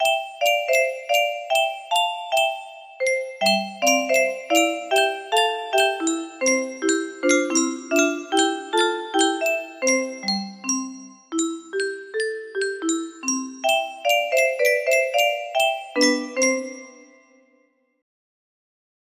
CANCION BARROCA music box melody